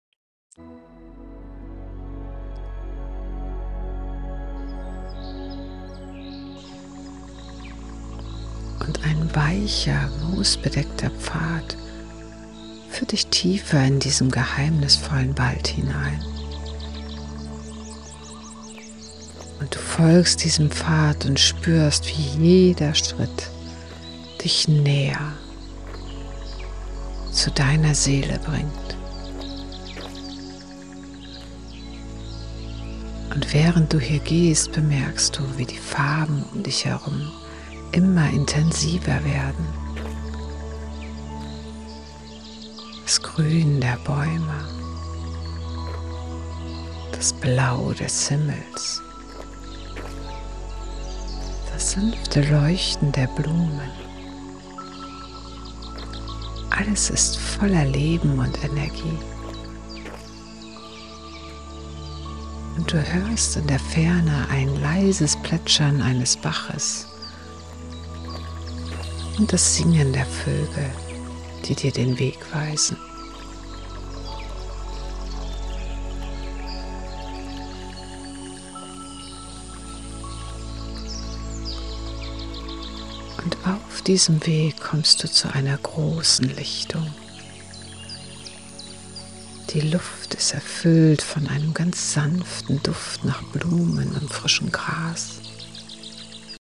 In der Soulful Awakening Membership erwarten dich drei transformative Meditationen, die dich auf deiner Reise zu innerer Klarheit und spirituellem Wachstum begleiten